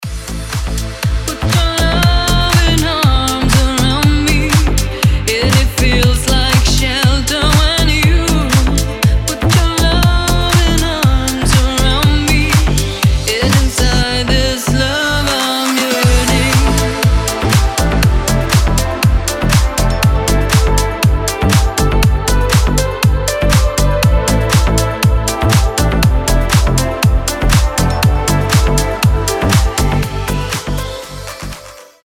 • Качество: 320, Stereo
deep house
retromix
nu disco
Vocal House